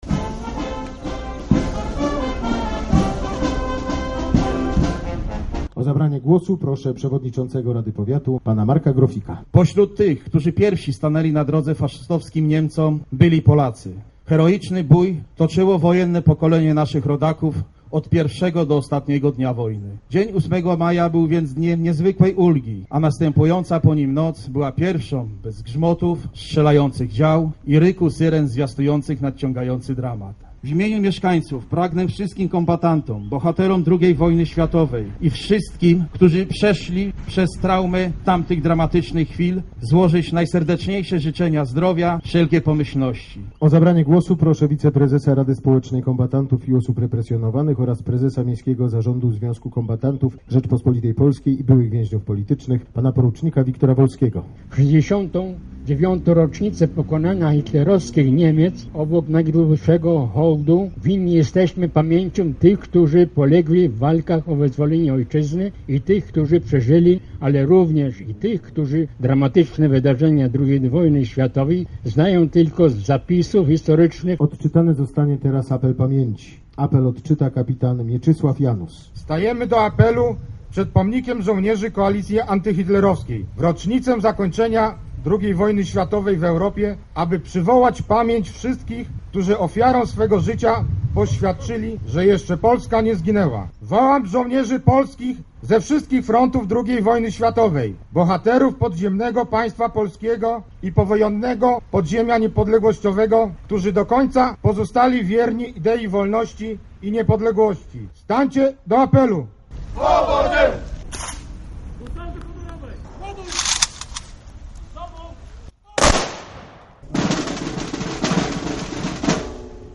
0508_ii_ws.jpgDzisiaj mija 69 lat od formalnego zakończenia II wojny światowej. Głogowianie oddali hołd ofiarom tego okresu pod Pomnikiem Koalicji Antyhitlerowskiej. Były przemówienia, wieńce i salwy honorowe.